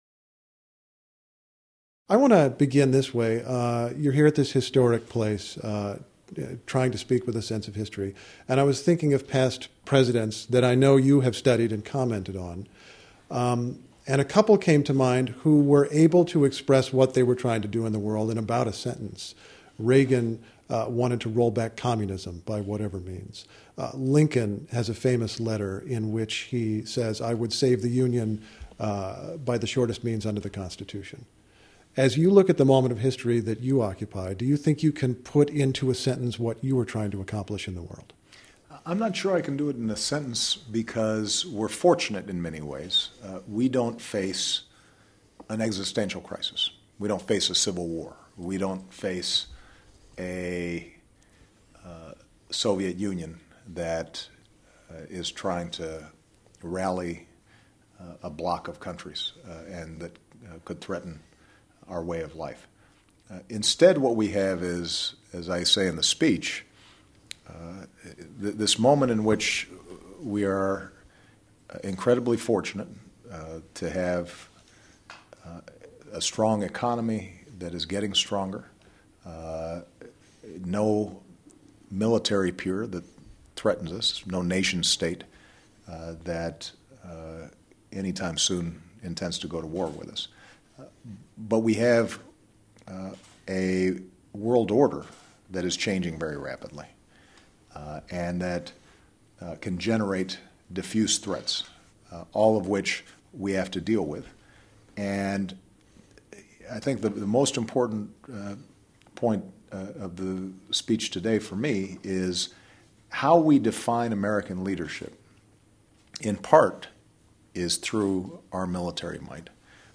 U.S. President Barack Obama talks with NPR's Steve Inskeep about foreign policy, his remaining White House priorities and his effort to close Guantanamo Bay prison